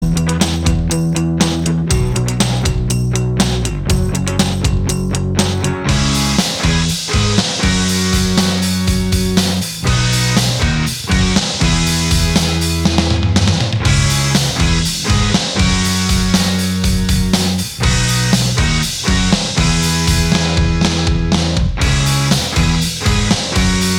Minus Lead Guitar Rock 3:03 Buy £1.50